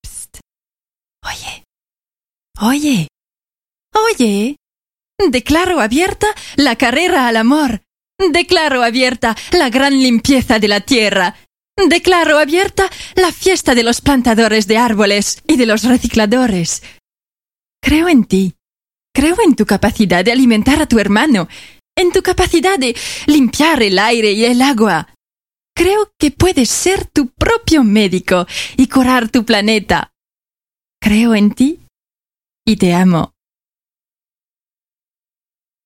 poème espagnol
20 - 60 ans - Soprano